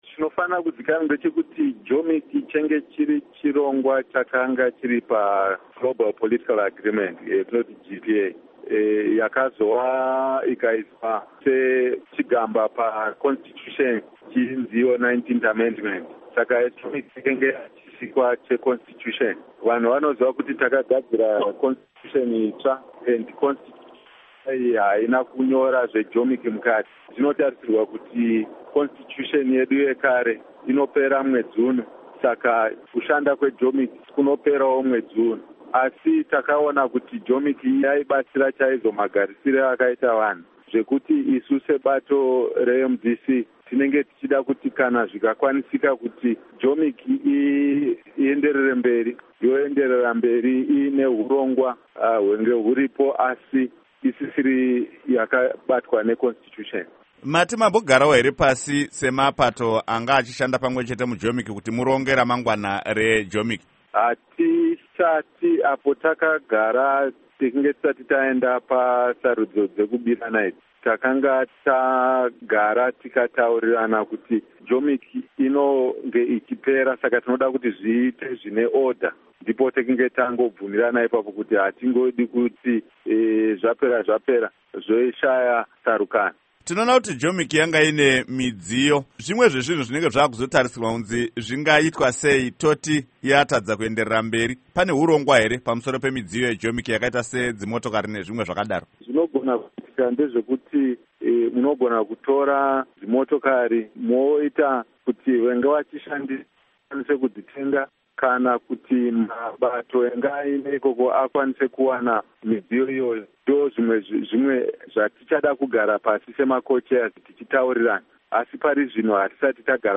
Hurukuro naVaElton Mangoma